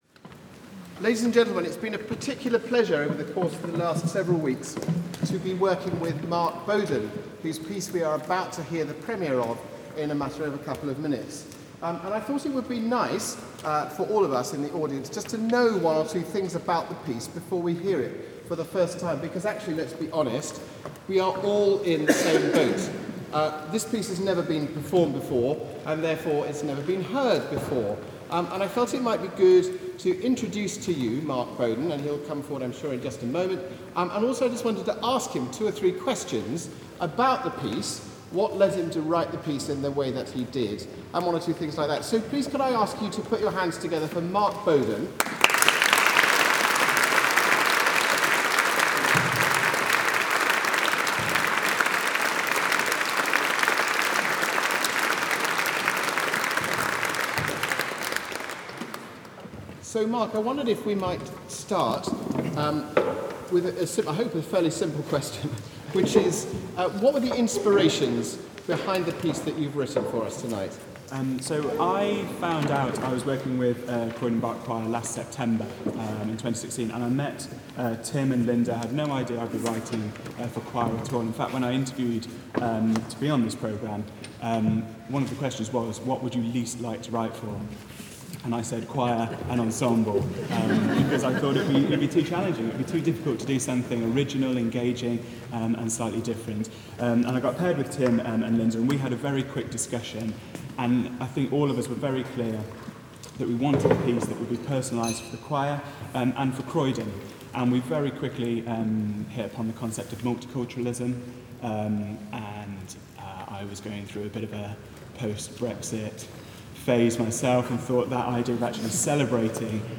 Composer